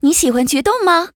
文件 文件历史 文件用途 全域文件用途 Choboong_amb_03.ogg （Ogg Vorbis声音文件，长度0.0秒，0 bps，文件大小：17 KB） 源地址:游戏语音 文件历史 点击某个日期/时间查看对应时刻的文件。